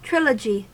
Ääntäminen
Ääntäminen EN-US: IPA : [ˈtrɪ.lə.dʒi] Haettu sana löytyi näillä lähdekielillä: englanti Käännös Substantiivit 1.